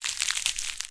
horror